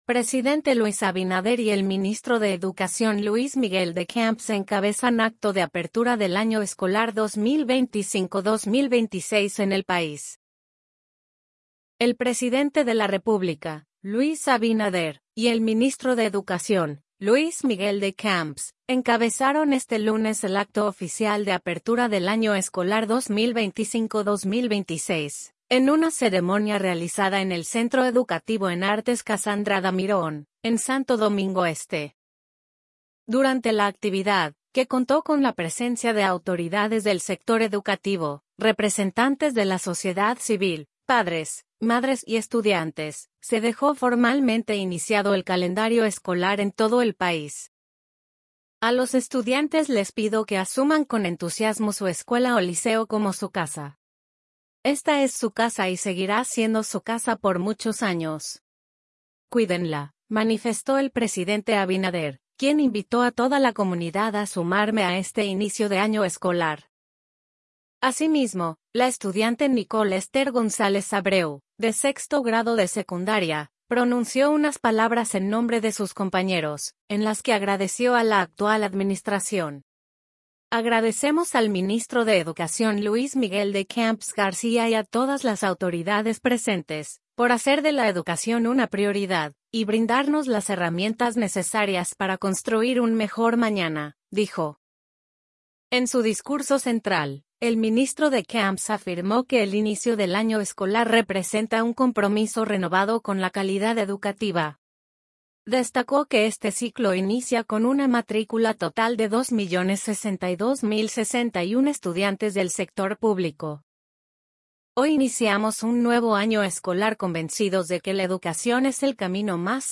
El presidente de la República, Luis Abinader, y el ministro de Educación, Luis Miguel De Camps, encabezaron este lunes el acto oficial de apertura del año escolar 2025-2026, en una ceremonia realizada en el Centro Educativo en Artes Casandra Damirón, en Santo Domingo Este.